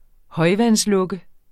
Udtale [ ˈhʌjvansˌlɔgə ]